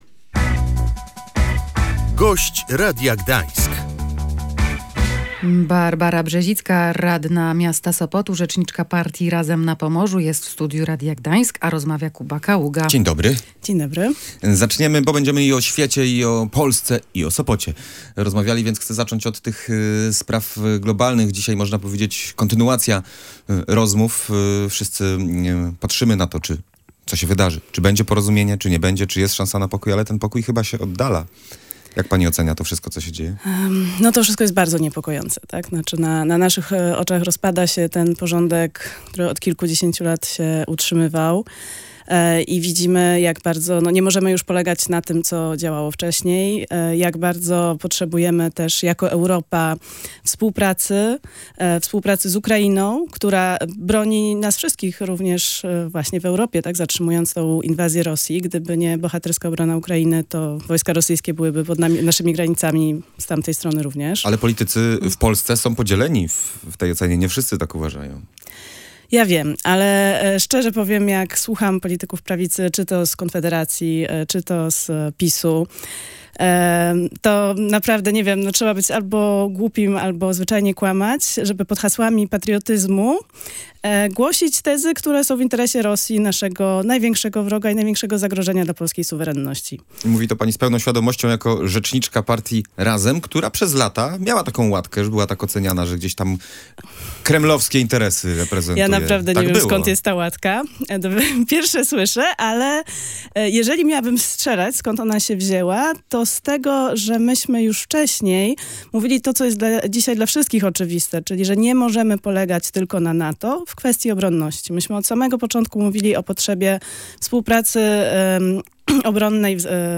Europa w kwestii obronności nie może liczyć wyłącznie na Stany Zjednoczone. Partia Razem zwracała na to uwagę od dawna – mówiła w Radiu Gdańsk Barbara Brzezicka, radna Sopotu i rzeczniczka partii Razem na Pomorzu.